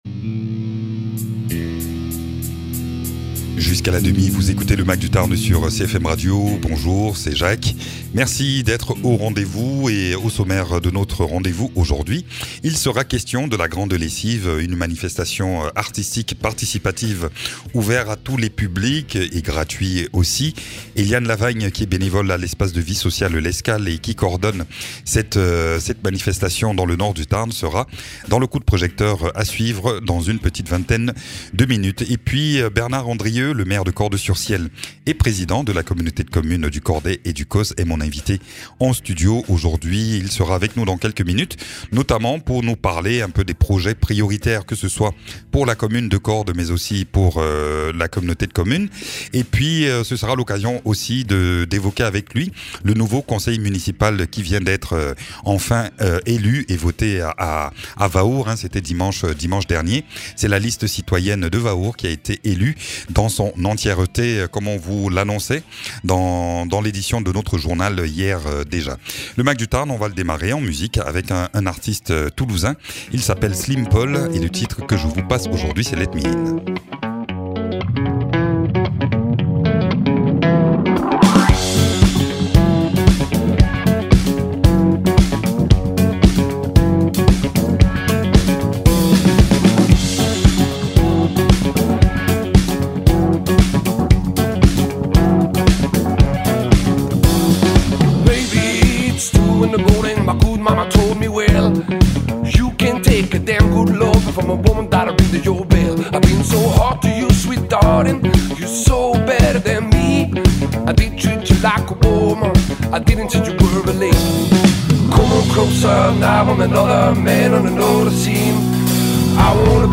Invité(s) : Bernard Andrieu, maire de Cordes-sur-Ciel et Président de la Communauté de Communes du Cordais et du Causse